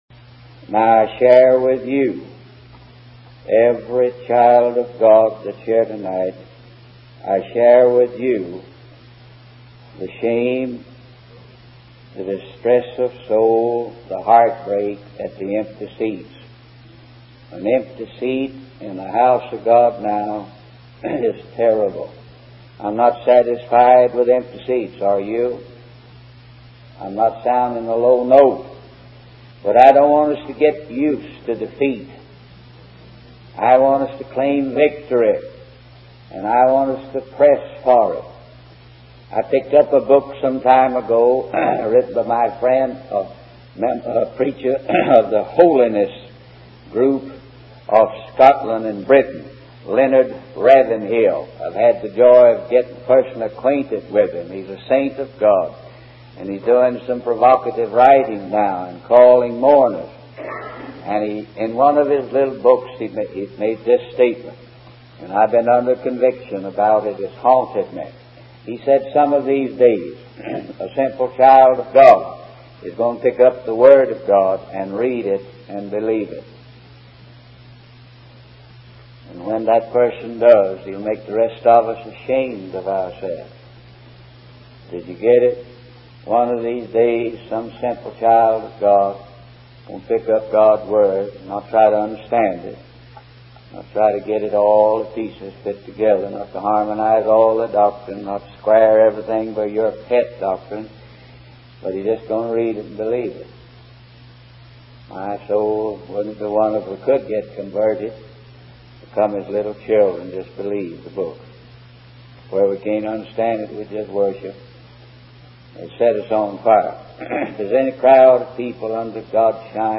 In this sermon, the preacher emphasizes the work of the Holy Ghost in gathering the objects of God's mercy and the subjects of Christ's death.